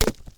hurt.ogg